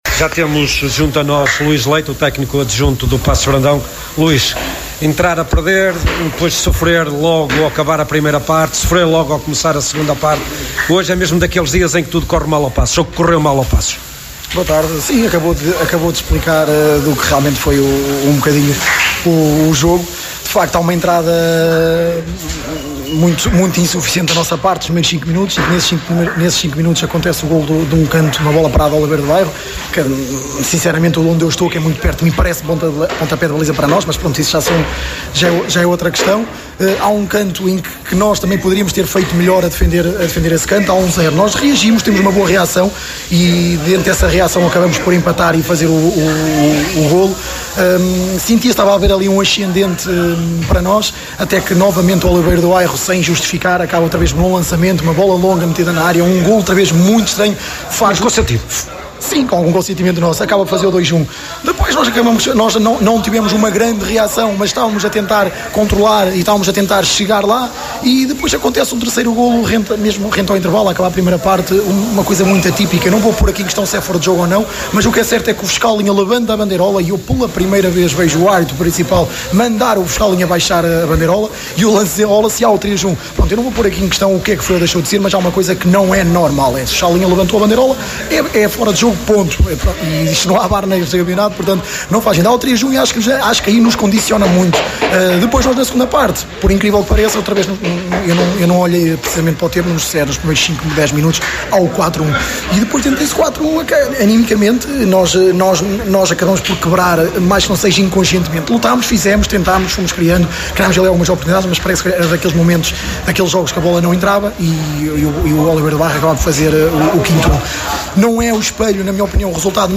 No final do jogo, a Sintonia procurou ouvir os intervenientes de ambas as equipas.